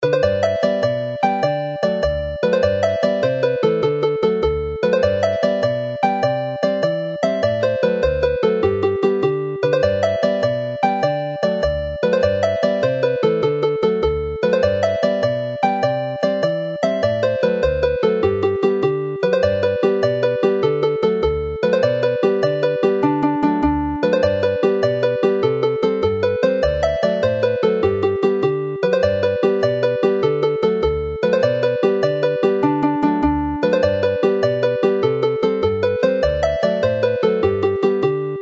This set comprises jigs from the excellent collection of 100 dance tunes in the second book of tunes published by the Welsh Folk Dance Society, Cadw Twmpath.